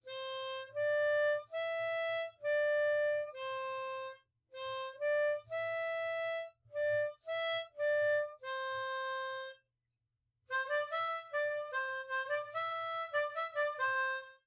Time to learn our Celtic folk melody on the harmonica.
Try to play lightly and with a slight lilt.